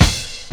Kick (78).wav